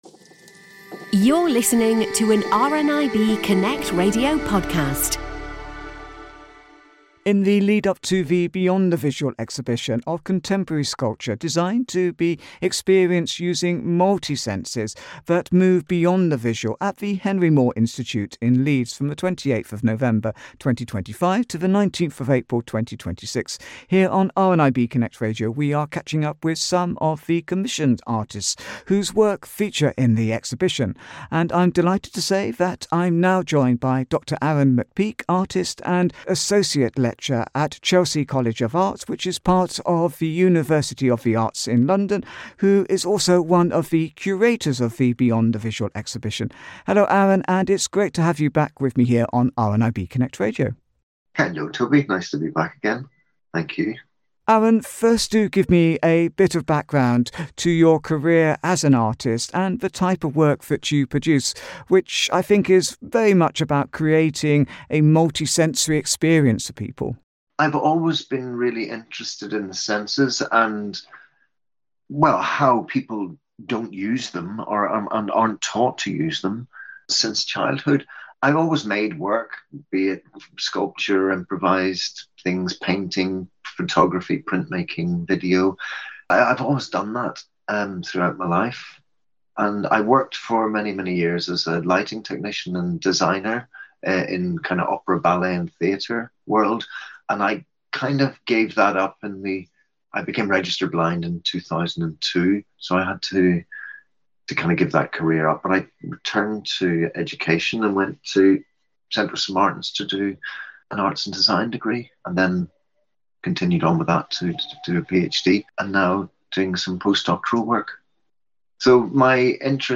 RNIB Connect Radio’s